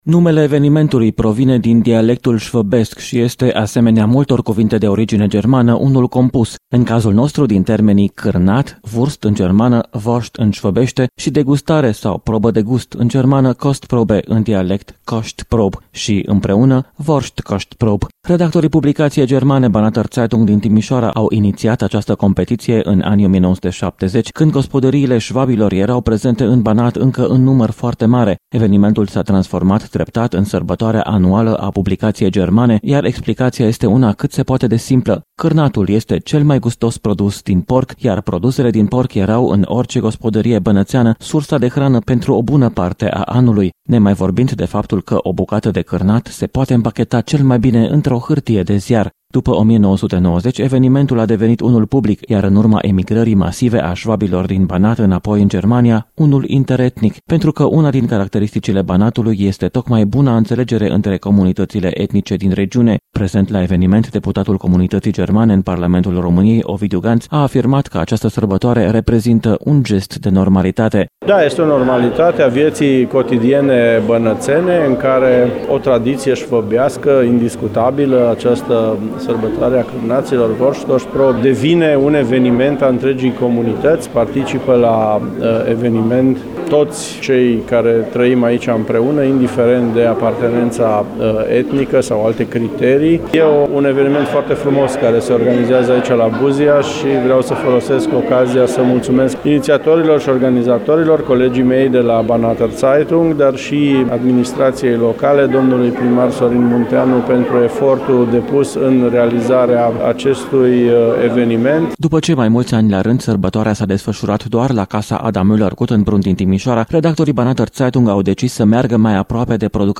Ediţia de anul acesta s-a desfăşurat, astăzi  la Buziaş.